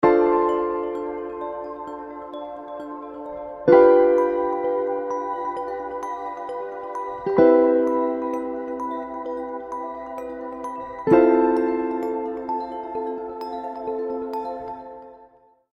onhold.mp3